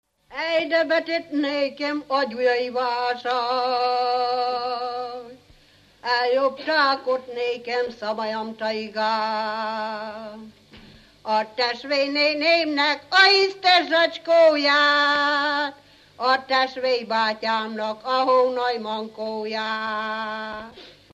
Felföld - Heves vm. - Füzesabony
ének
Műfaj: Koldusének
Stílus: 7. Régies kisambitusú dallamok
Szótagszám: 12.12
Kadencia: (4) 1